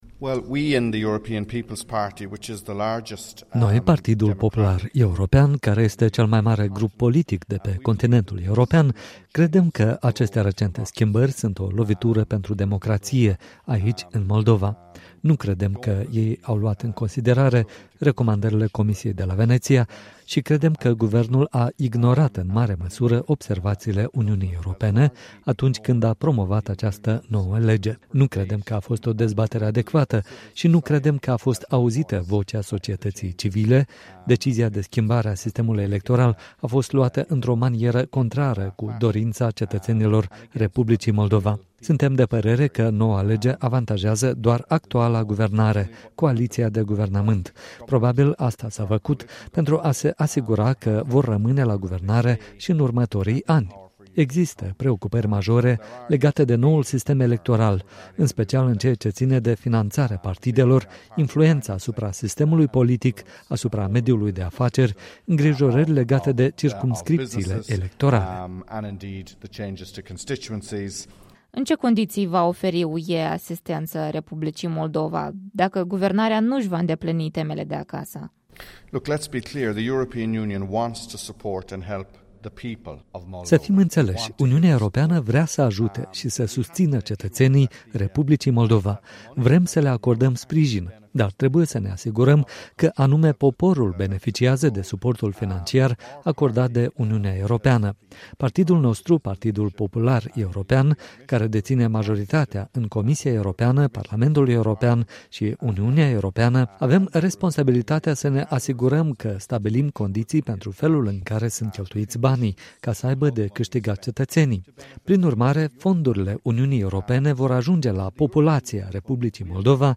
Dara Murphy, vicepreşedinte al Partidului Popular European, la congresul Partidului Acţiune şi Solidaritate
Interviu cu vicepreşedintele Partidului Popular European, Dara Murphy